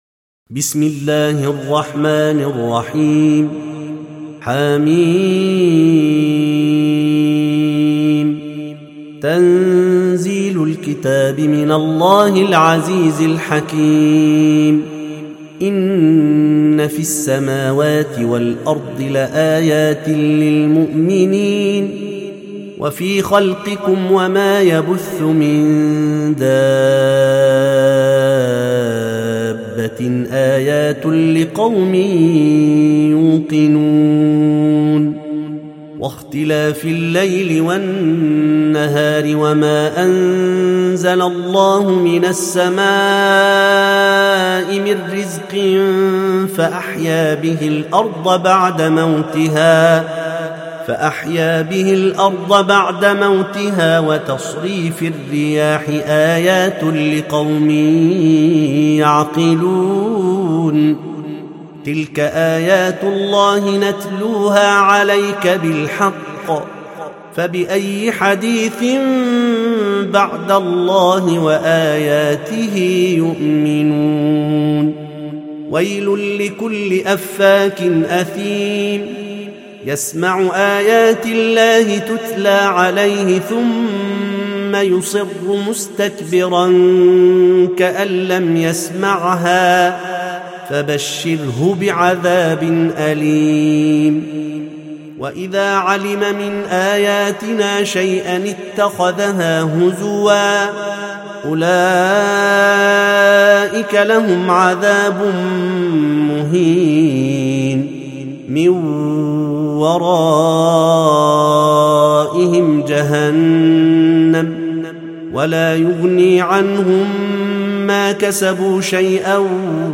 سورة الجاثية - المصحف المرتل (برواية حفص عن عاصم)